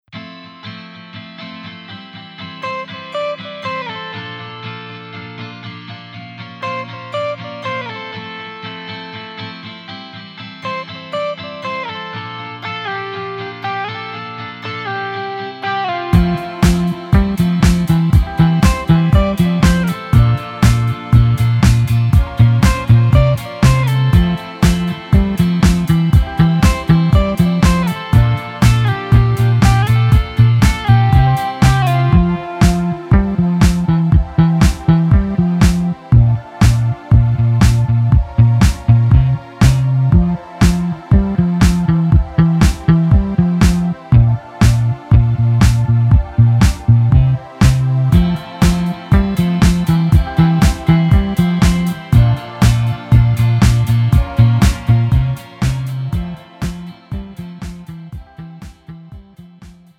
음정 여자키
장르 pop 구분 Pro MR